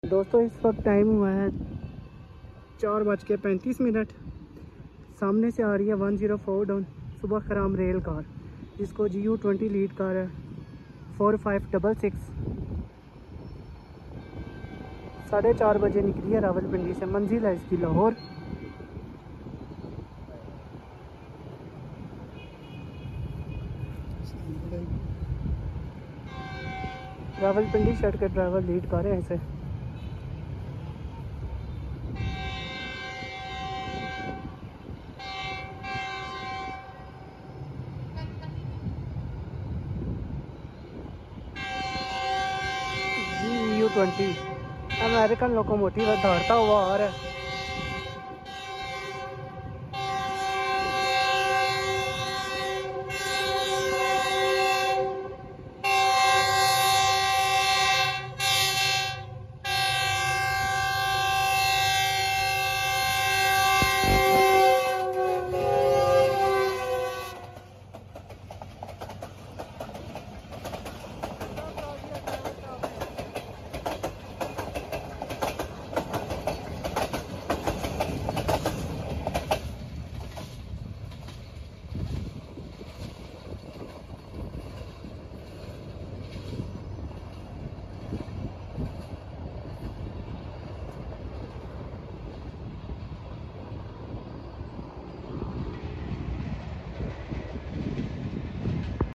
104 Dn Subak Kharam Leaving Sound Effects Free Download